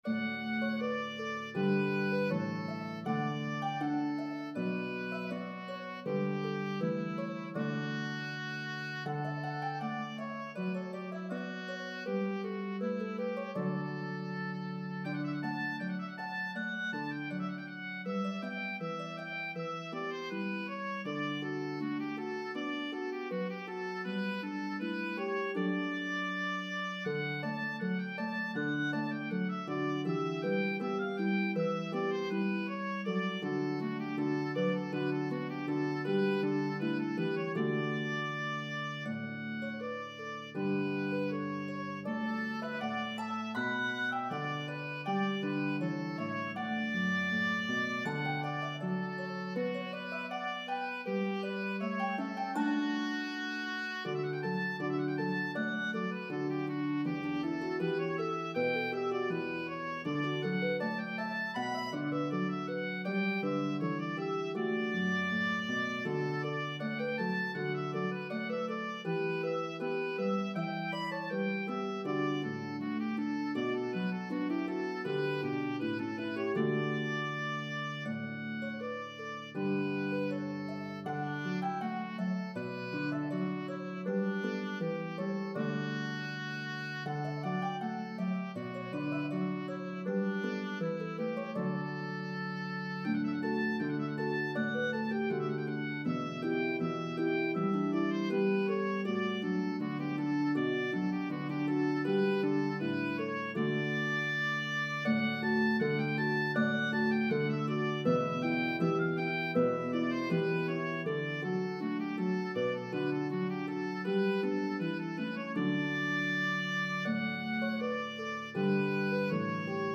Renaissance melodies